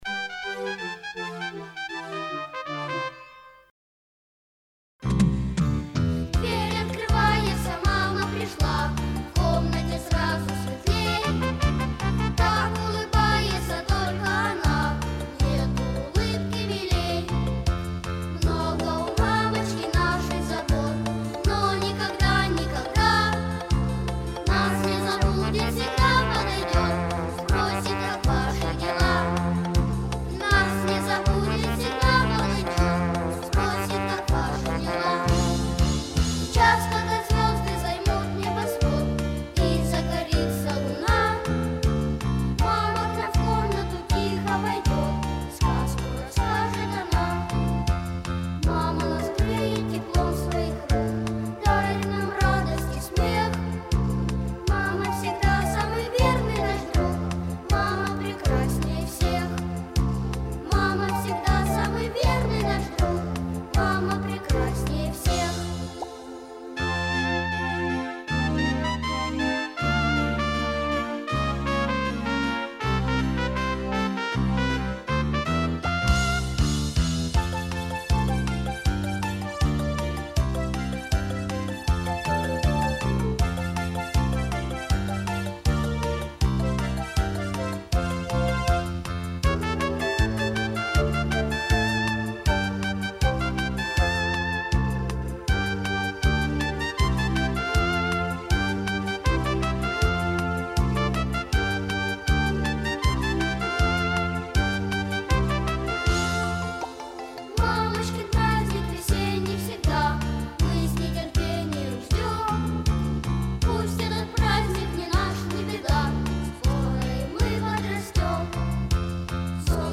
• Название: Детская песня
• Жанр: Детские песни